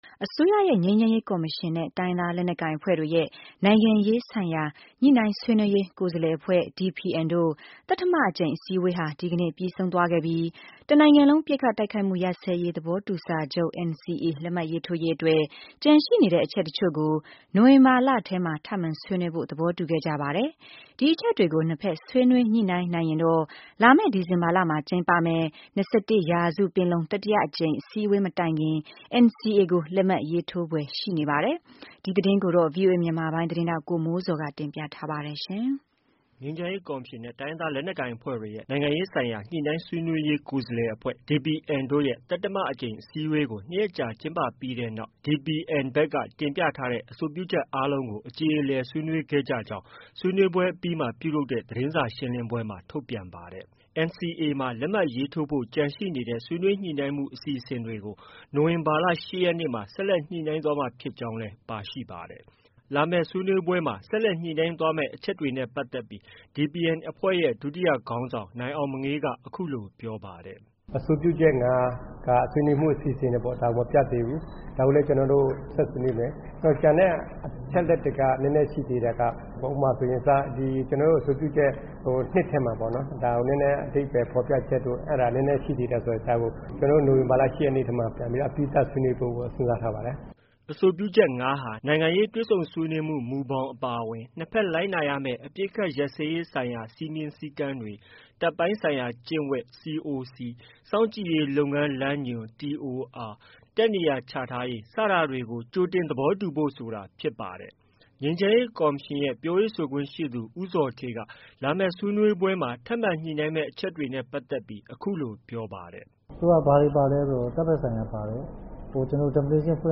ငြိမ်းချမ်းရေးကော်မရှင်ရဲ့ ပြောရေးဆိုခွင့်ရှိသူ ဦးဇော်ဌေးက လာမယ့်ဆွေးနွေးပွဲမှာ ထပ်မံညှိနှိုင်းမယ့် အချက်တွေနဲ့ပတ်သက်ပြီး အခုလို ပြောပါတယ်။